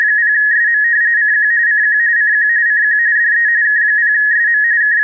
Using the K3's AFSK TX filter in this scenario, you would transmit the following spectrum, still with the broken wire. You can see one or two of the harmonics that make it through the passband of the DSP filter.
The tones are of equal amplitude--this picture happened to catch a character that was "space-heavy".
buzz_with_filter.wav